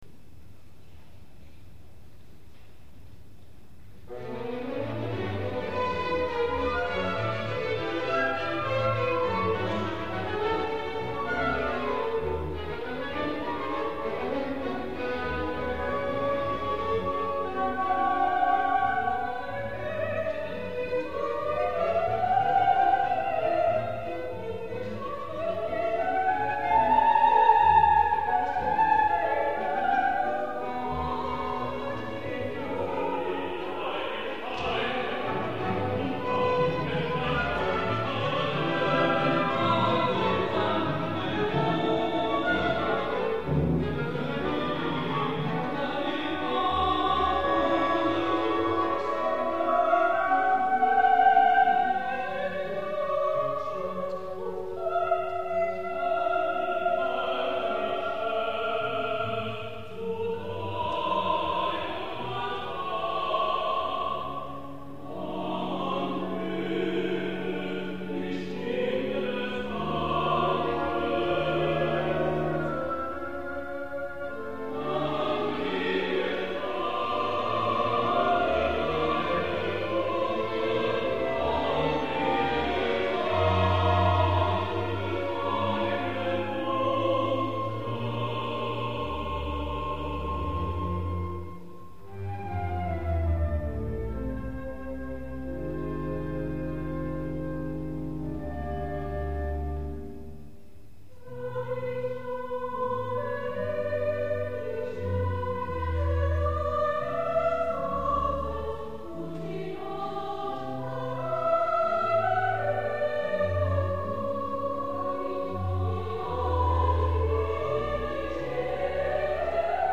Kantate für Sopran-, Tenor- und Bariton-Solo, vier- bis sechsstimmiger gemischter Chor,
Flöte, Oboe, Klarinette, Fagott, 2 Trompeten, Posaune, Pauken, Streichorchester und Orgel
Solisten und Chor